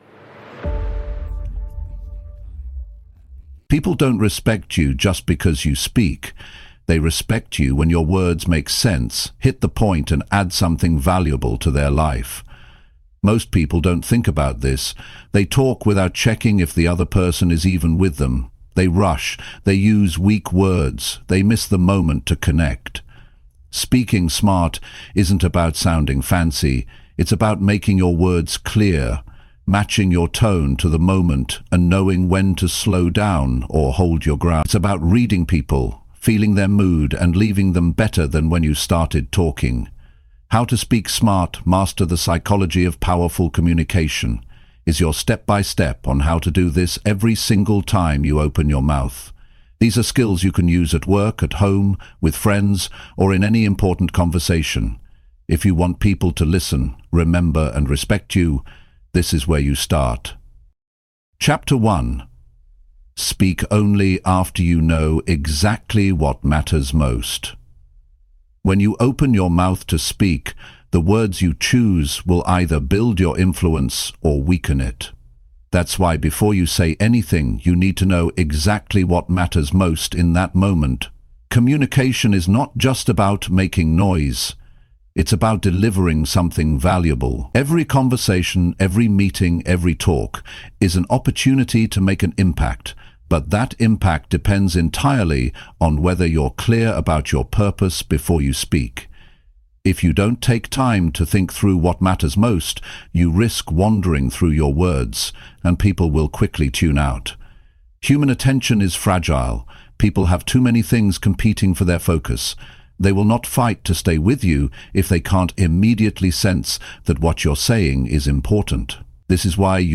Libros Narrados